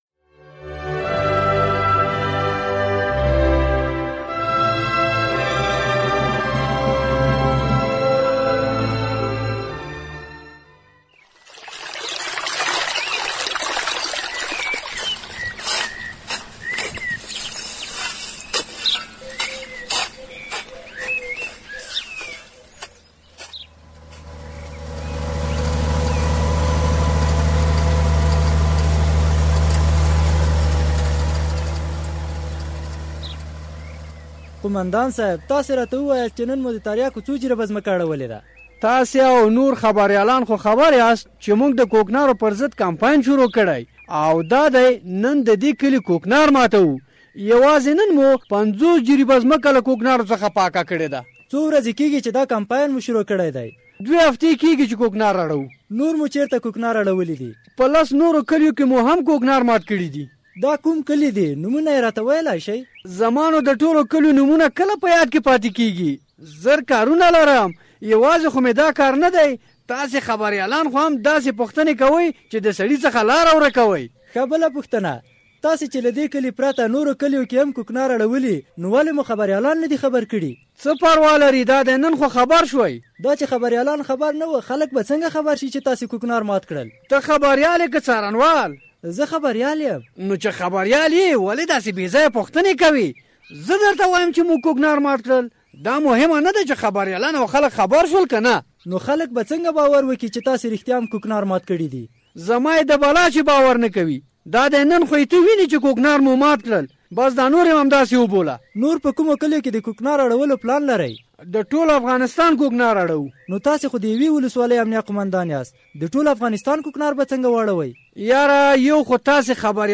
ډرامه: د قوماندان بشير له خوا د کوکنار د اړولو ترشا څه کیسه ده؟